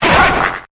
SIRFETCHD.ogg